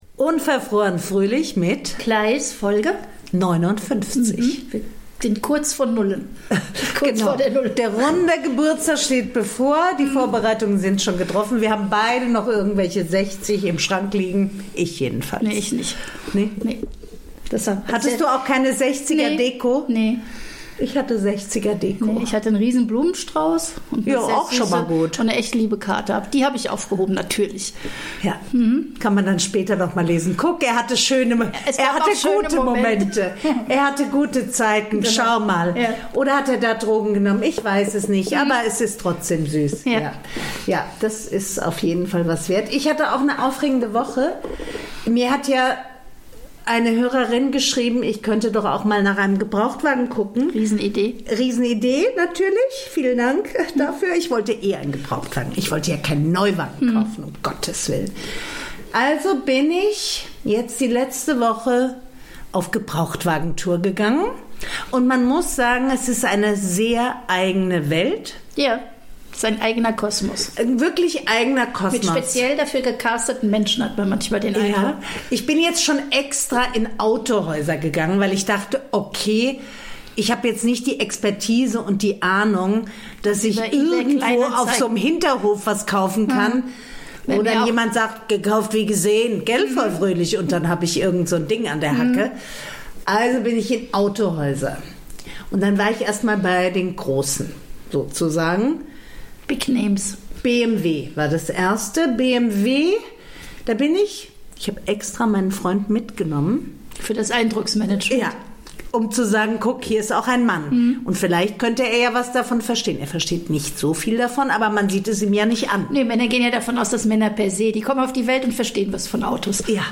reden die beiden Podcasterinnen über Autohauserfahrungen, über die Causa Fernandes, über technische Tricks und über Freundschaftsdienste.